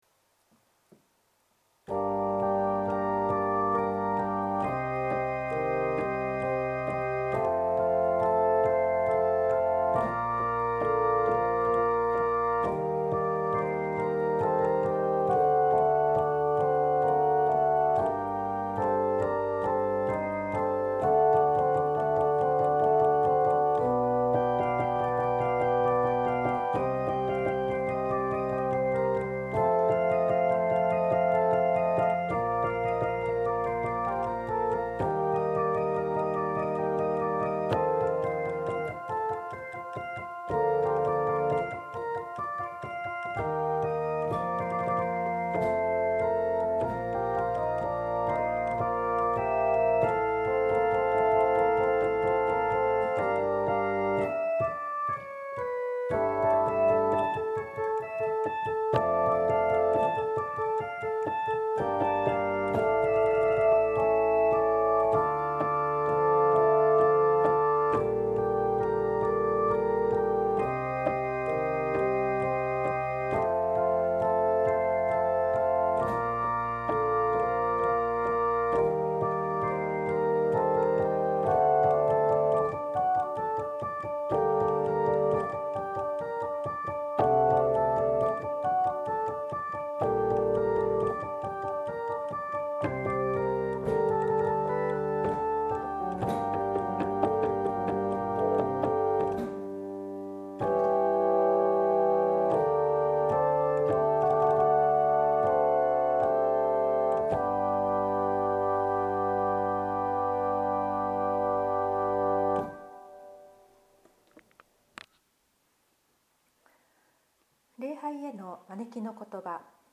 左下の三角形のアイコンをクリックすることにより礼拝の音声を聞くことができます。